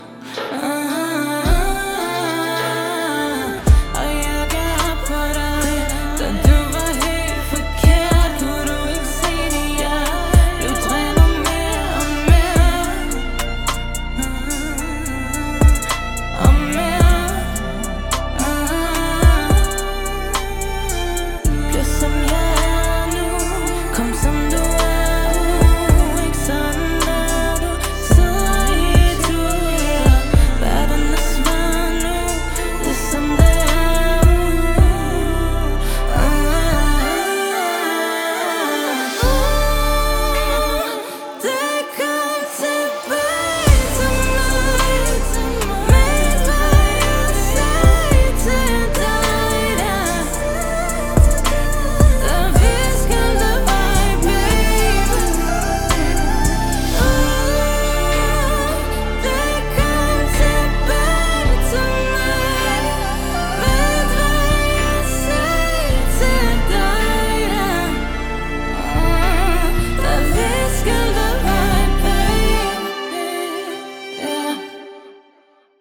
• Jazz
• Pop
• R'n'b
• Soul